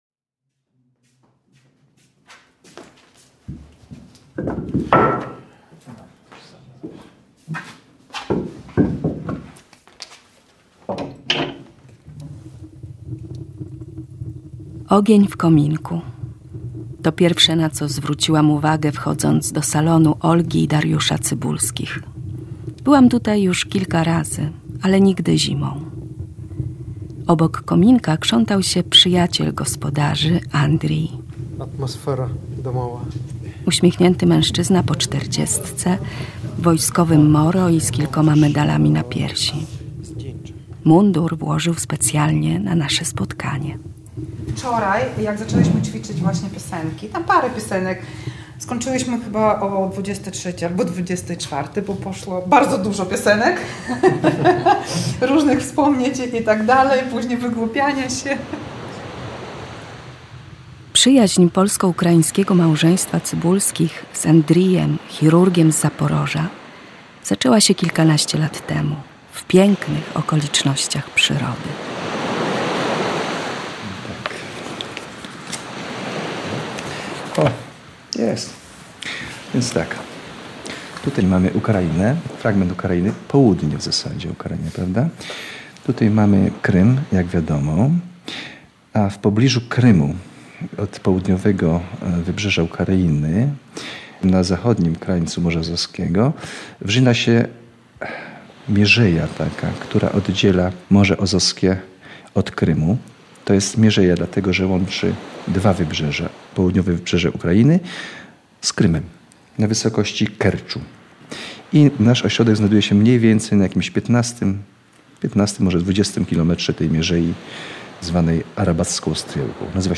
„Przyjaciel z wakacji” – to tytuł reportażu, którego posłuchamy dziś po godzinie 21.00.